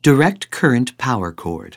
DC_power_cord.wav